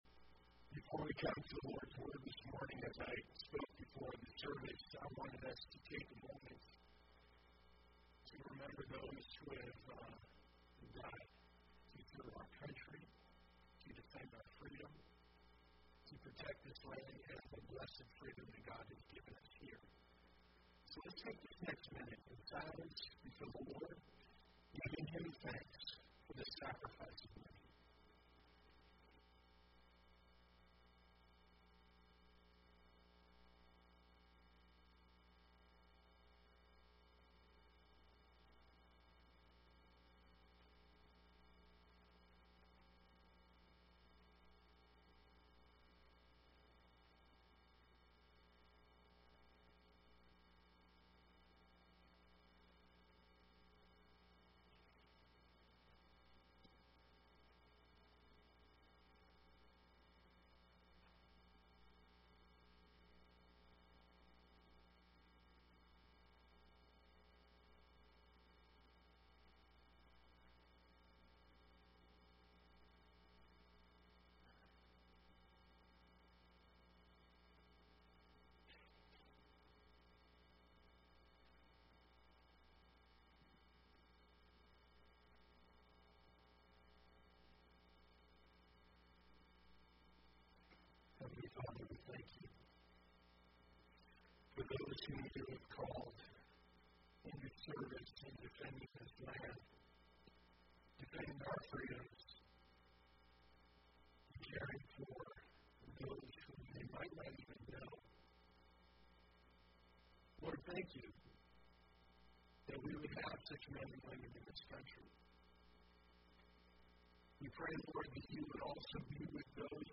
So That You May Believe Passage: John 15:12 – 16:4 Services: Sunday Morning Service Download Files Bulletin Previous Next